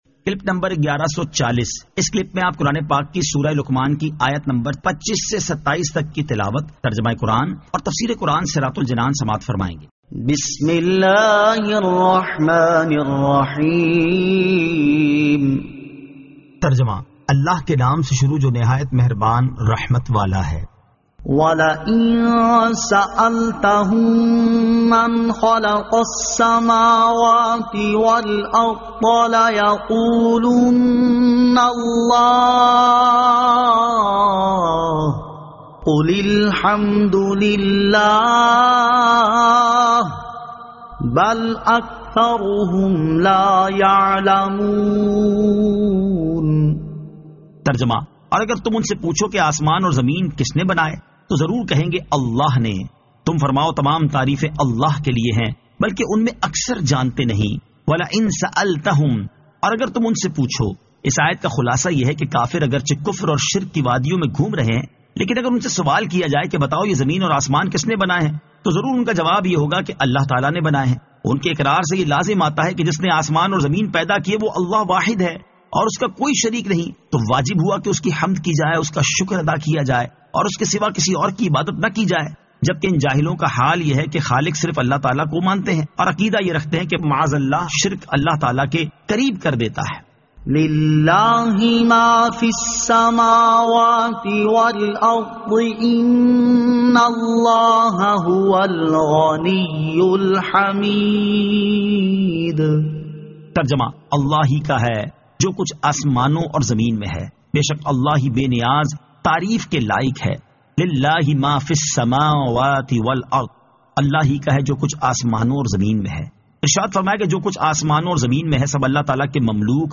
Surah Luqman 25 To 27 Tilawat , Tarjama , Tafseer